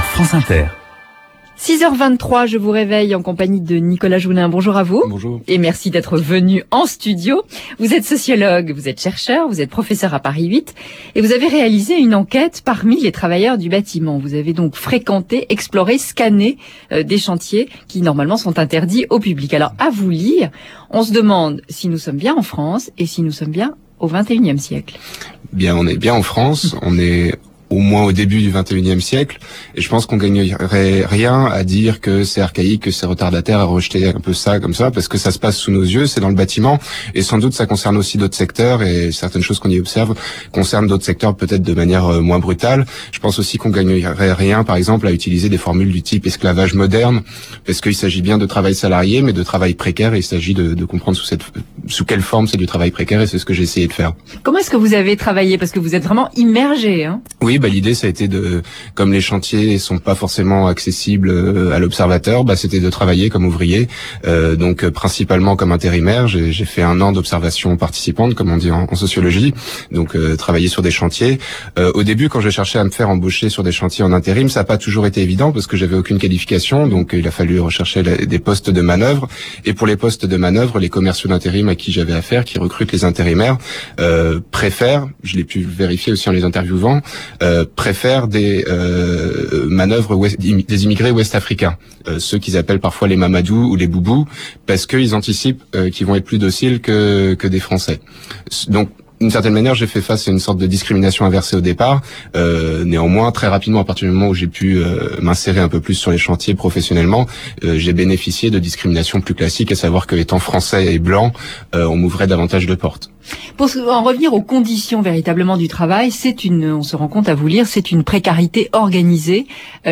Entendu donc ce matin sur France-Inter, une courte présentation de l’ouvrage Chantier interdit au public.